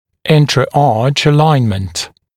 [ˌɪntrə’ɑːʧ ə’laɪnmənt][ˌинтрэ’а:ч э’лайнмэнт]выравнивание в пределах одной зубной дуги